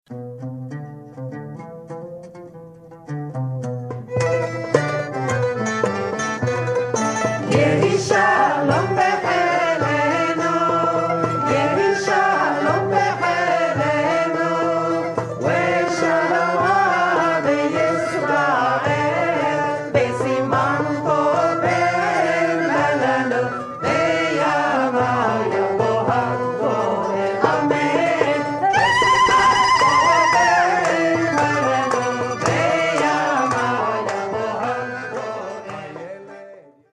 Live in India! CD